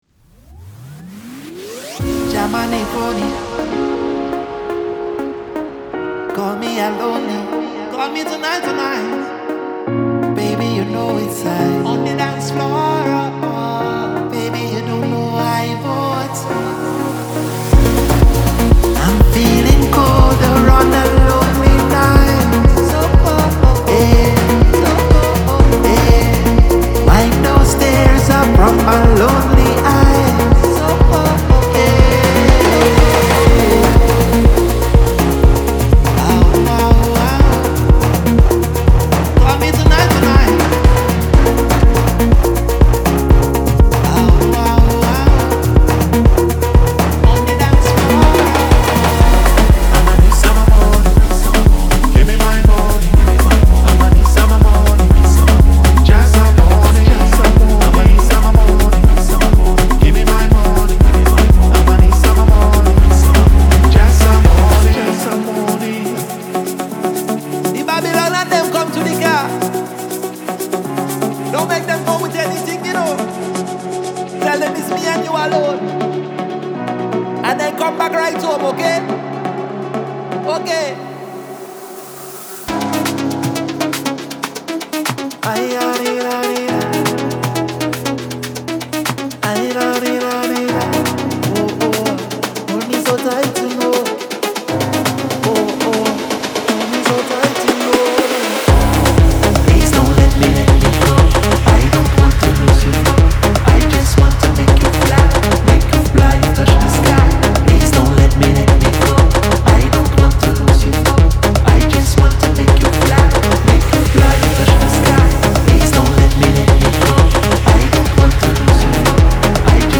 デモサウンドはコチラ↓
Genre:Afro House
120, 122, 123 BPM
209 Wav Loops (Bass, Synths, Drums, Vocals, Fx, Fills)